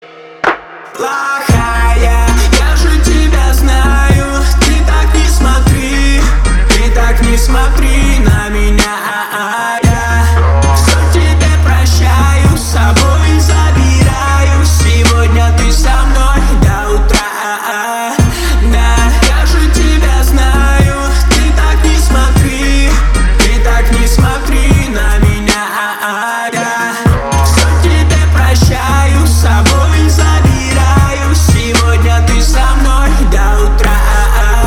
• Качество: 320, Stereo
мужской вокал
Хип-хоп
русский рэп